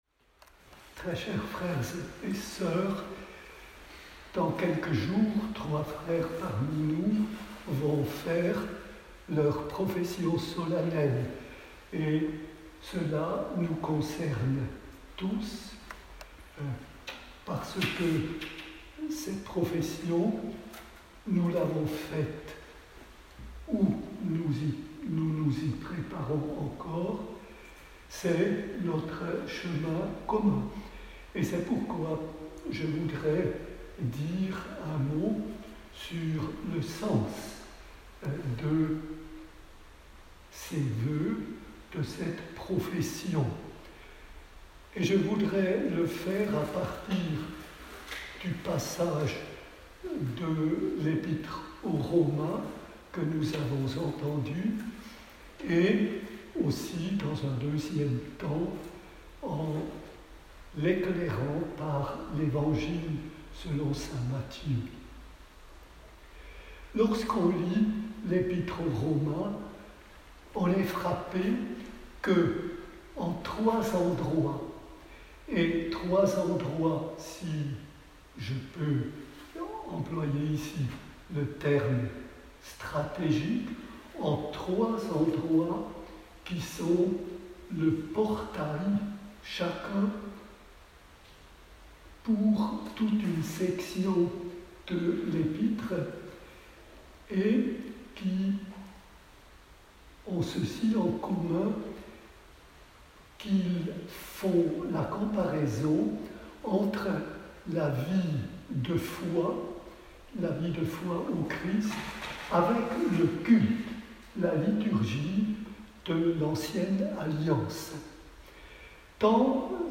Enregistrement en direct